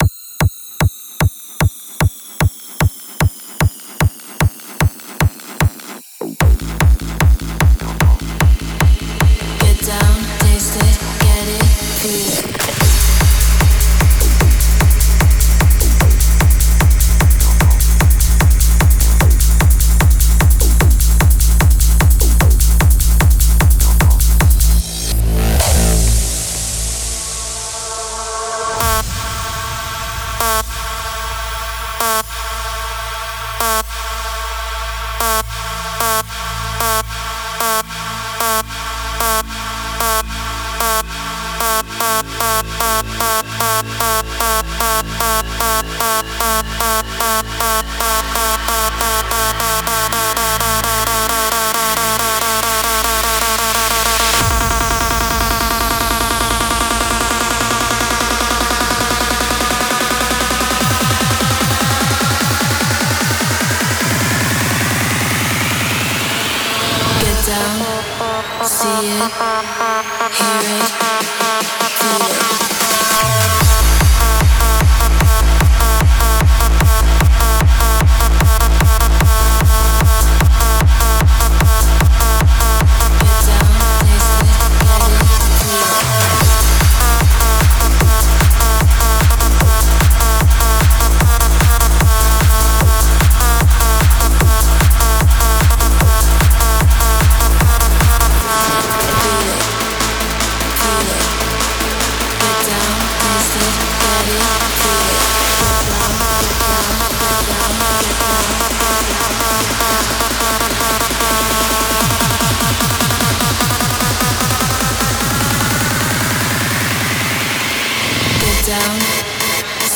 试听文件为低音质，下载后为无水印高音质文件 M币 15 超级会员 M币 8 购买下载 您当前未登录！